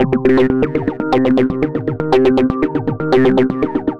Repeat Lead.wav